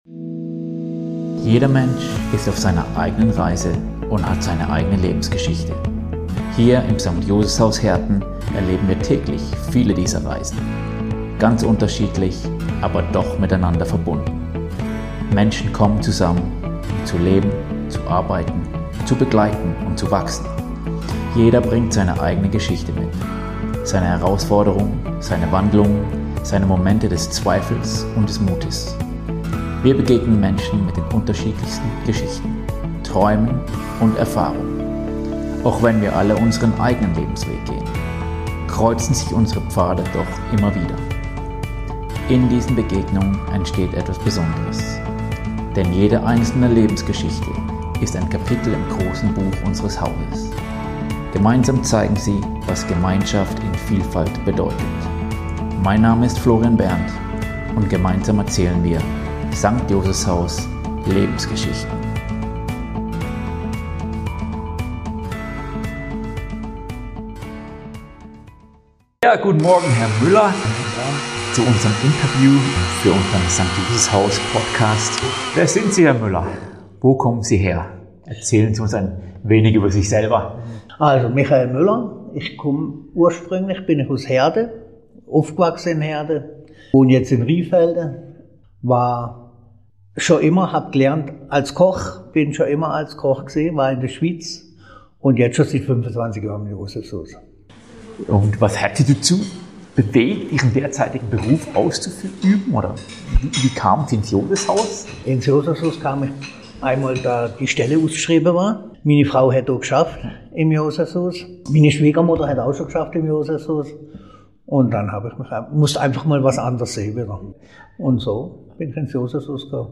Ein Gespräch über Werte, Hingabe und die Kraft, Menschen durch